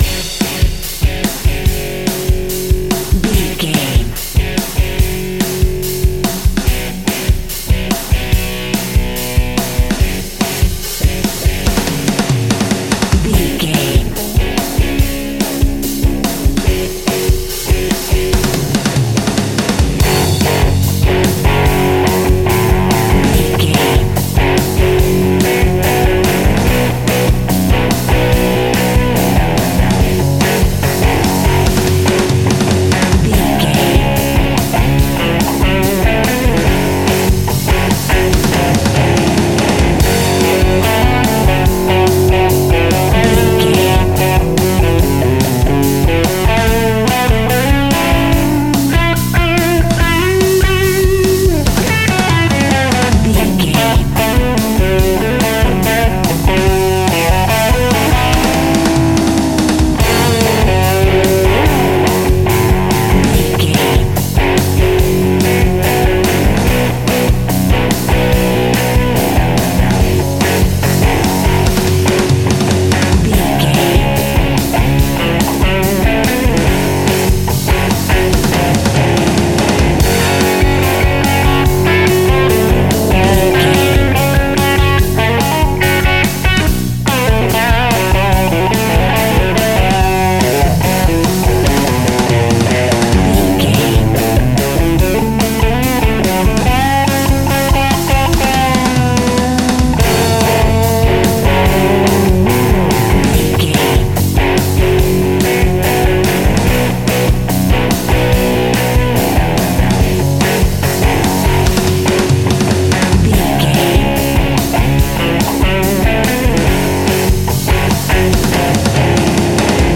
Epic / Action
Fast paced
Ionian/Major
F#
hard rock
blues rock
distortion
rock instrumentals
Rock Bass
heavy drums
distorted guitars
hammond organ